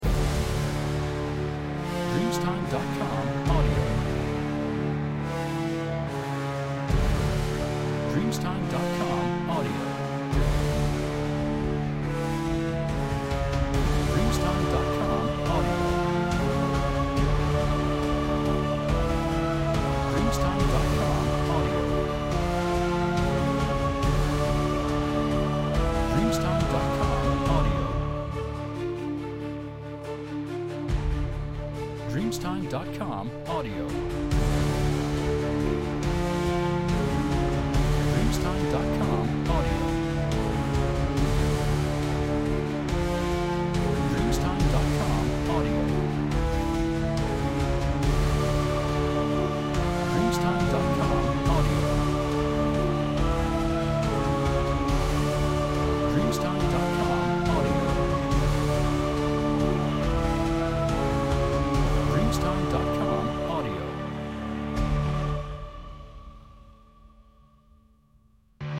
Retroceso de la orquesta de terror